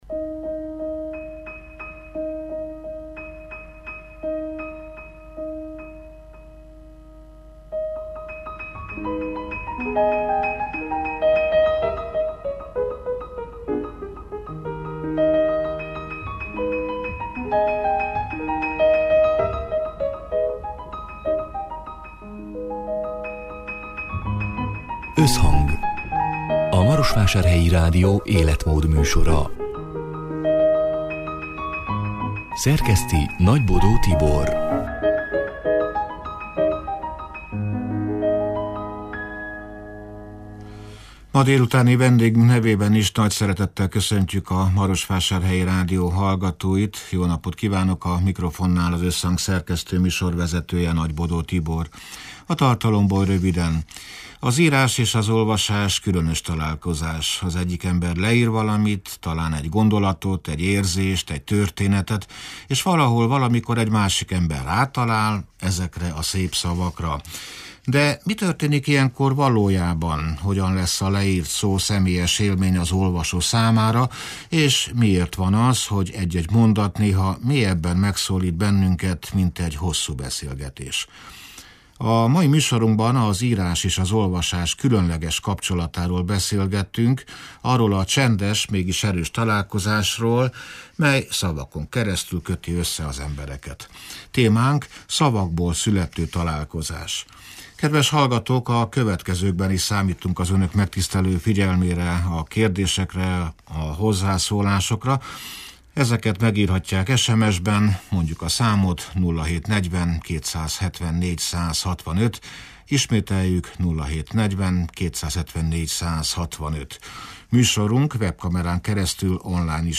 (elhangzott: 2026. március 11-én, szerdán délután hat órától élőben)